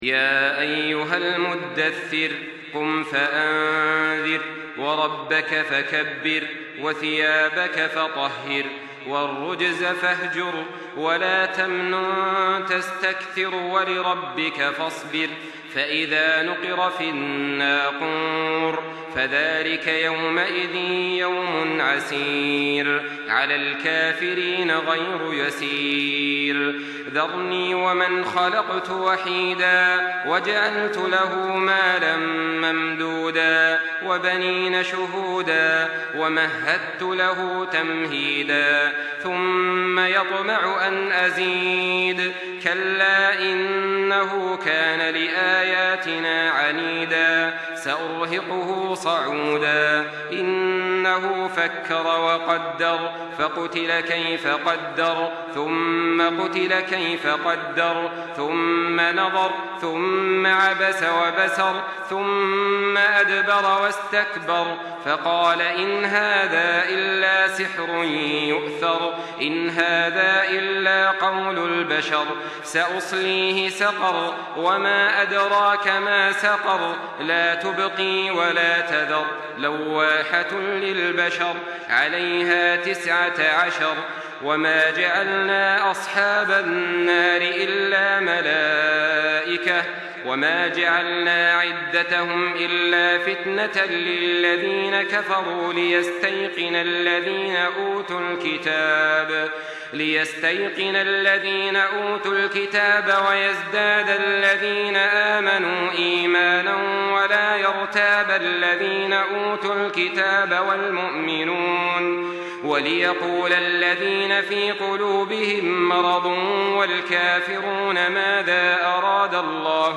تحميل سورة المدثر بصوت تراويح الحرم المكي 1424
مرتل